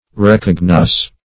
Search Result for " recognosce" : The Collaborative International Dictionary of English v.0.48: Recognosce \Rec"og*nosce\ (r[e^]k"[o^]g*n[o^]s), v. t. [L. recognoscere.